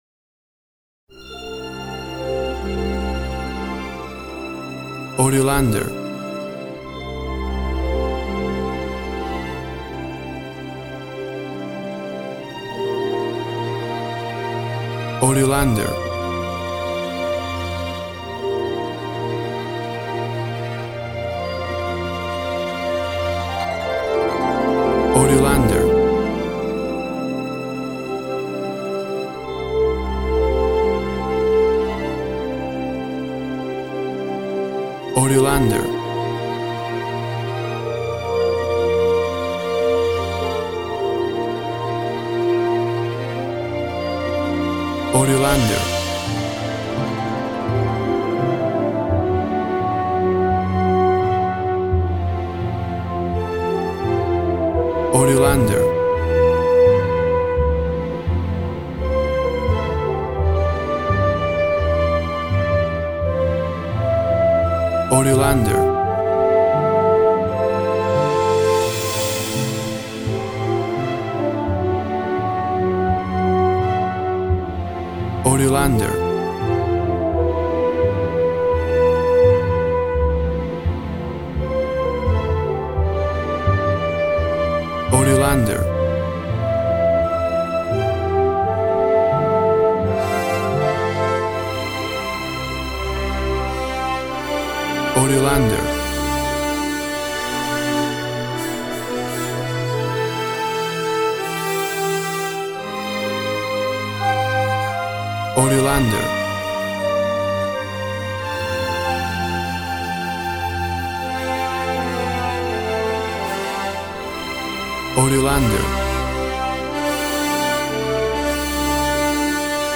Cinematic soundtrack. Imagination and fantasy.